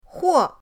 huo4.mp3